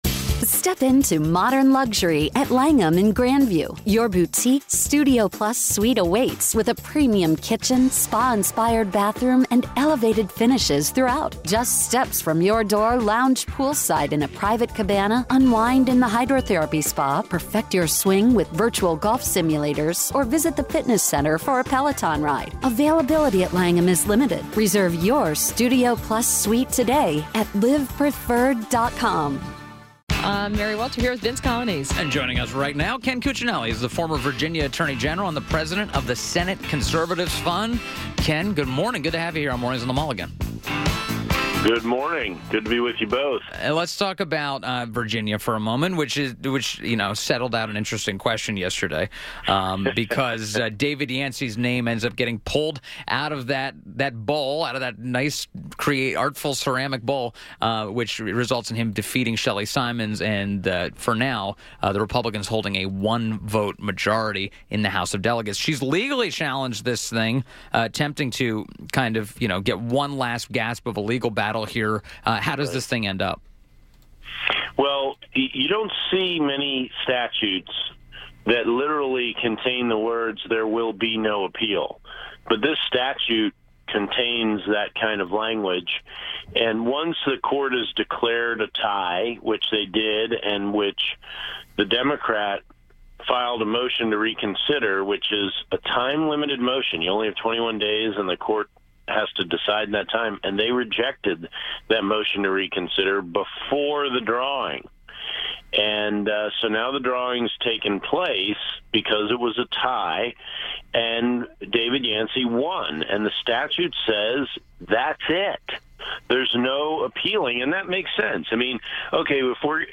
WMAL Interview - KEN CUCCINELLI - 01.05.18
INTERVIEW -- KEN CUCCINELLI - former VA Attorney General and President of Senate Conservatives Fund